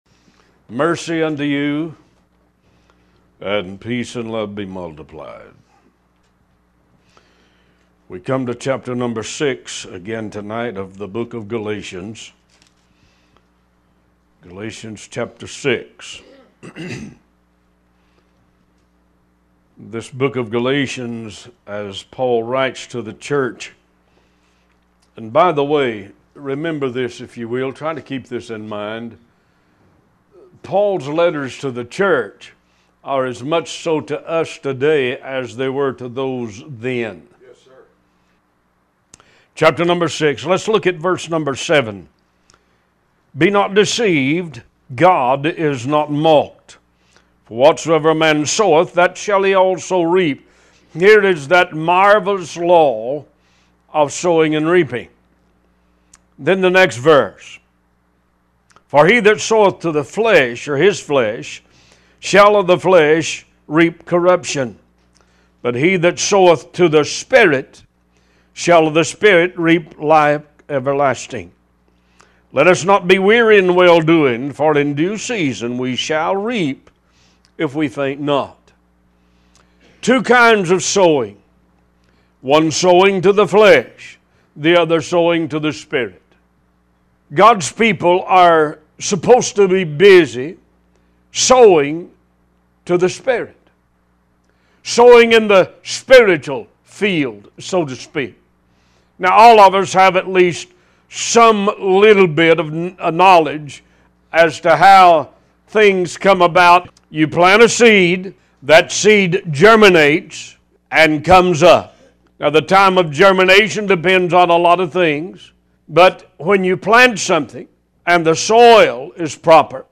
Talk Show Episode, Audio Podcast, One Voice and Holy Ghost In Modern Church part 29 on , show guests , about Holy Ghost In Modern Church, categorized as Health & Lifestyle,History,Love & Relationships,Philosophy,Psychology,Christianity,Inspirational,Motivational,Society and Culture